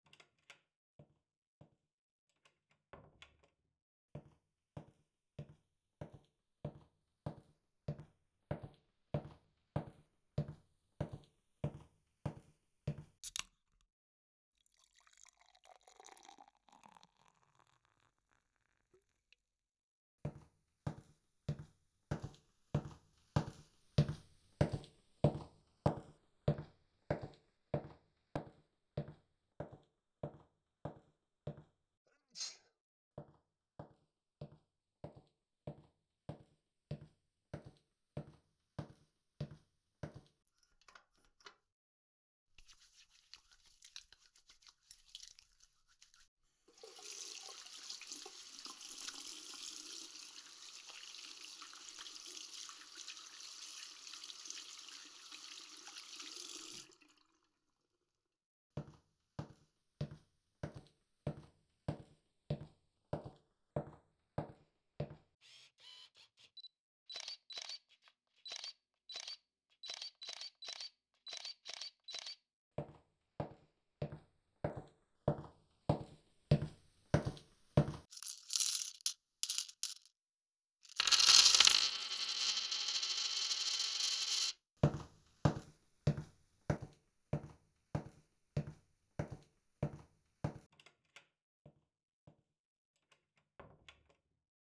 Binaural Synthesis (Virtual 3D Audio) Samples:
Elevation perception is highly sensitive to the shape of your ear, so a “bad fit” subject will have confusing elevations!